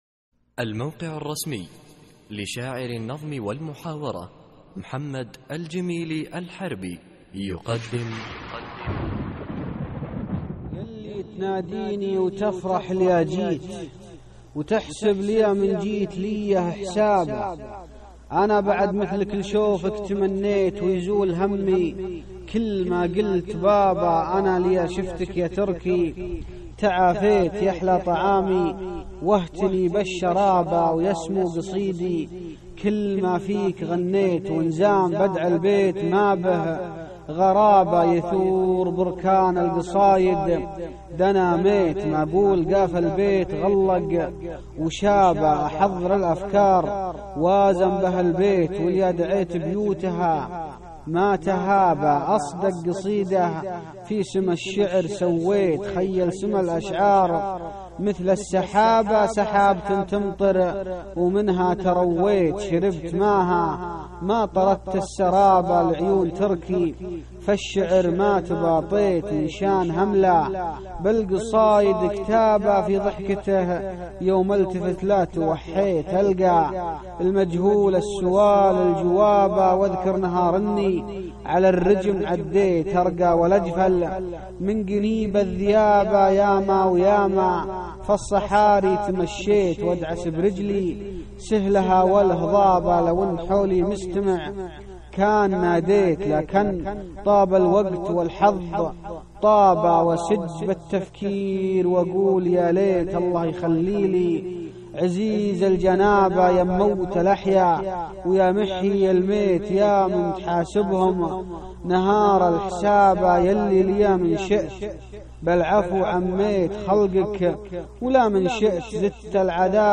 القصـائــد الصوتية
اسم القصيدة : لعيون تركي ~ إلقاء